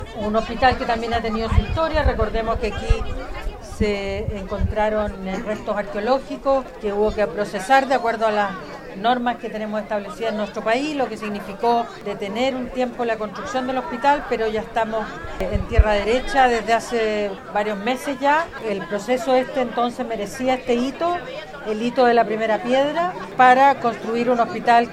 En este sentido, la autoridad del MOP, Jessica López, destacó que se hayan retomado las obras tras los hallazgos arqueológicos.